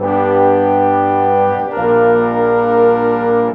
Rock-Pop 01 Brass 09.wav